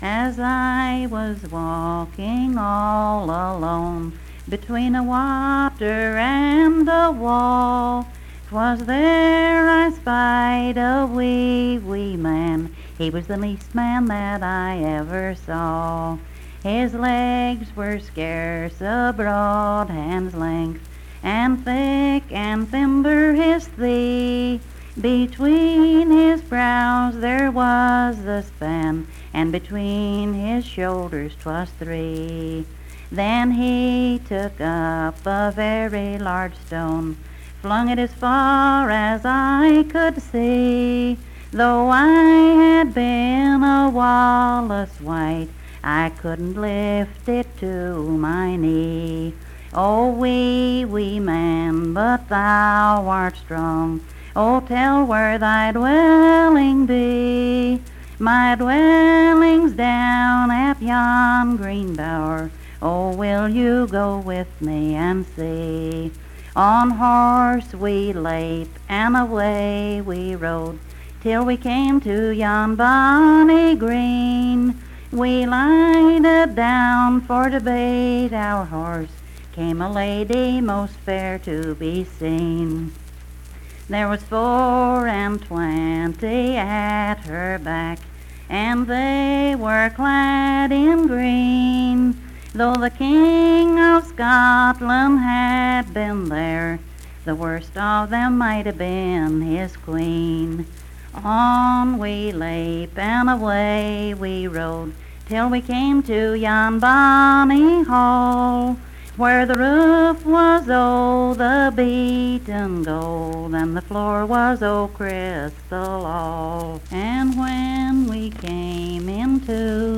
Unaccompanied vocal music
Verse-refrain 9(4).
Performed in Coalfax, Marion County, WV.
Voice (sung)